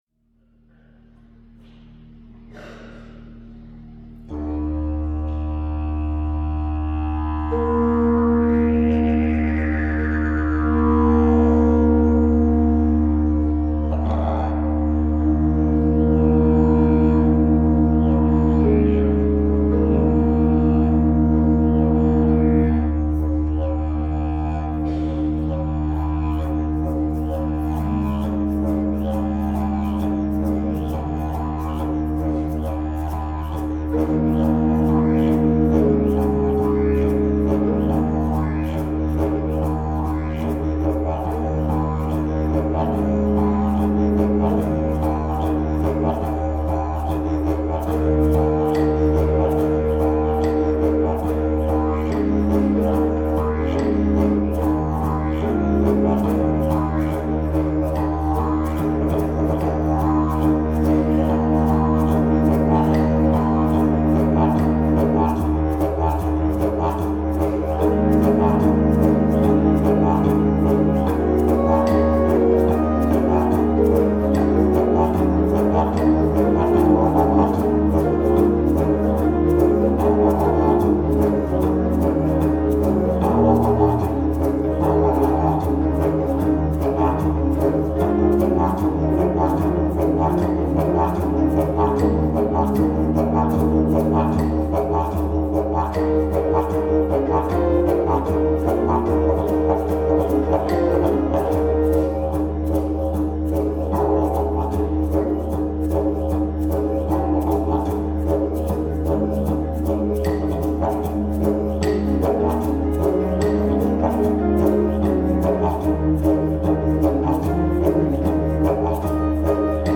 Die One Man Band
Drums, Sitar, Didgeridoo,